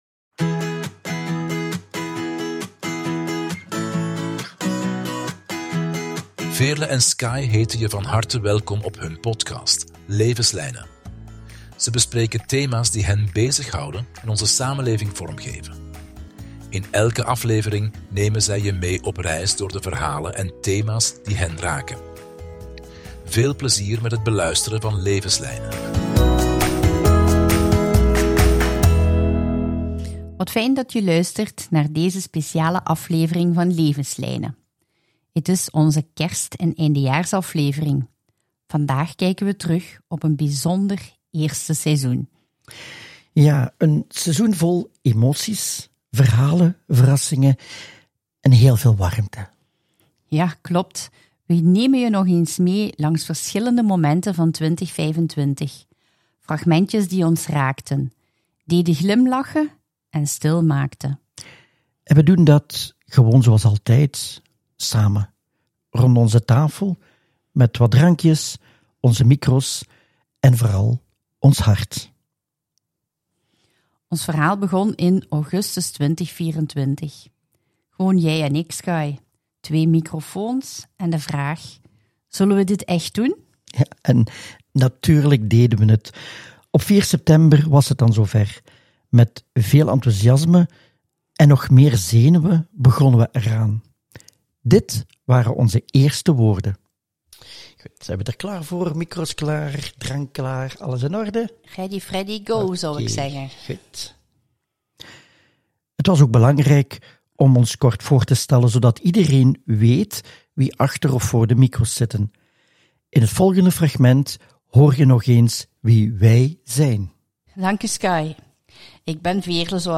Aan de hand van fragmenten uit alle afleveringen nemen ze je opnieuw mee langs verhalen over identiteit, veerkracht, geloof, kwetsbaarheid, hoop en verbinding.